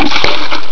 PLATSCH.WAV